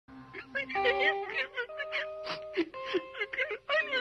pe-de-pano-chorando.mp3